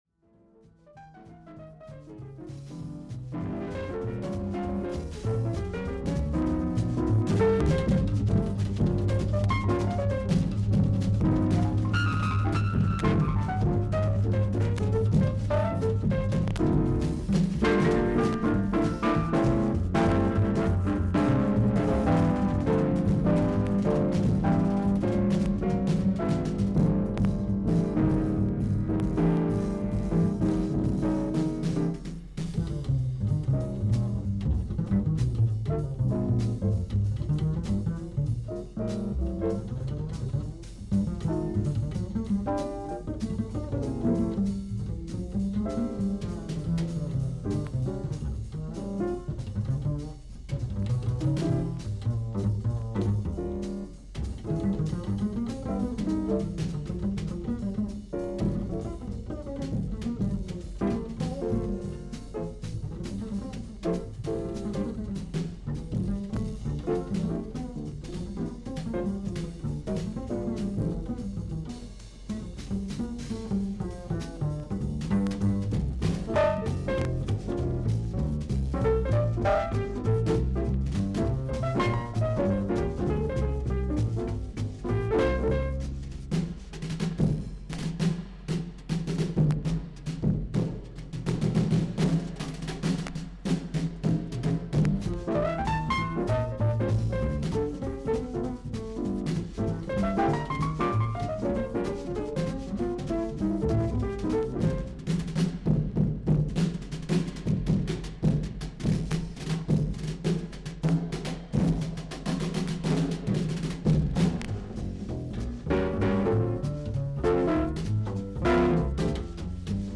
B2前半にキズあり、少々周回ノイズあり。
少々サーフィス・ノイズあり。クリアな音です。
イギリスのジャズ・ドラマー。
ベースとドラムスの緊張感のある演奏が楽しめます。